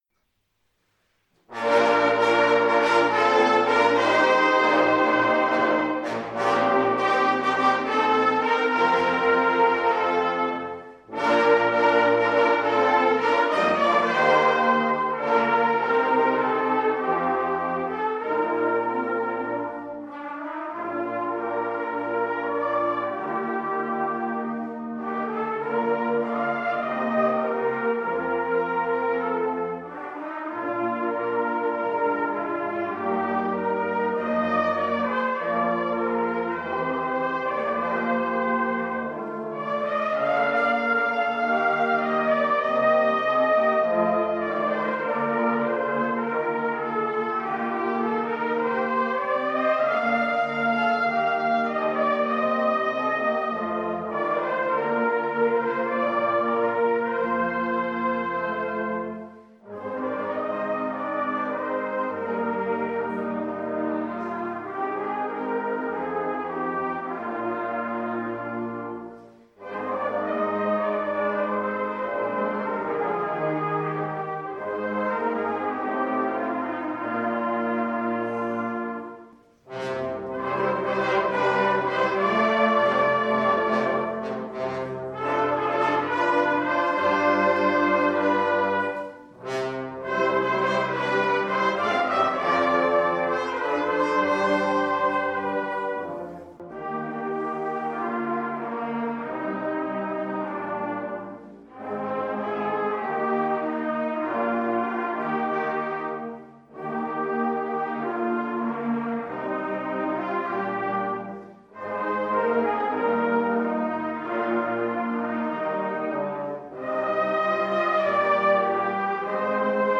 Konzert-2022.mp3